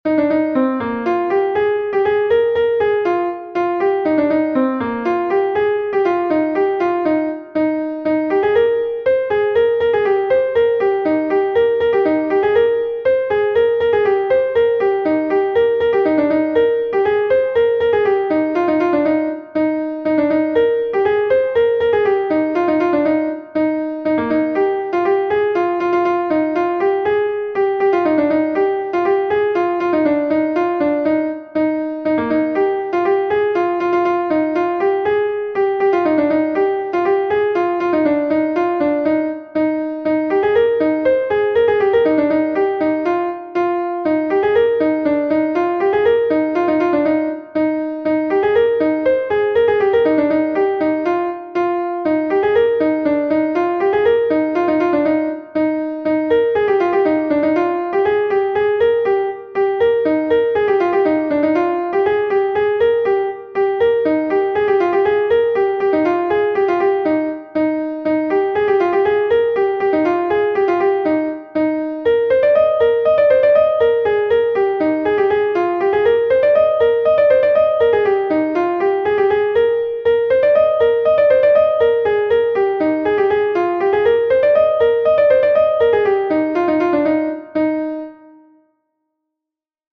Gavotenn Sant-Tudal III is a Gavotte from Brittany